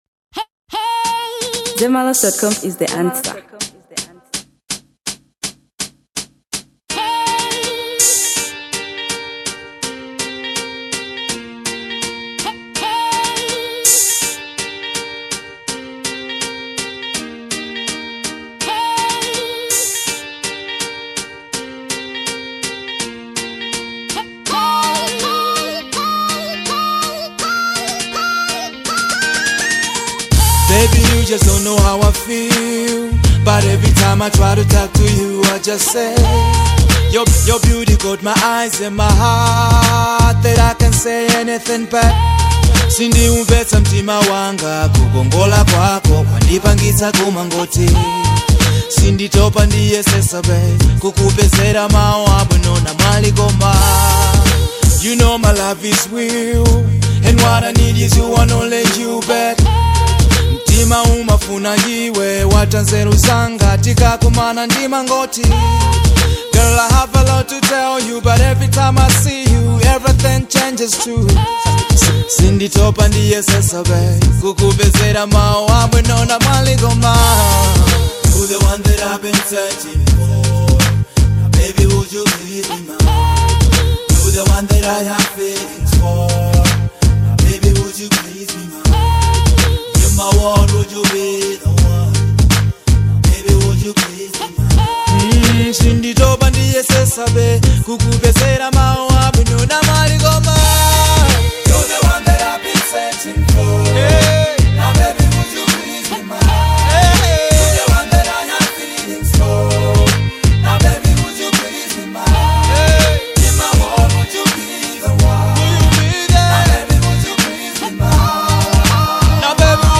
Fusion • 2025-10-27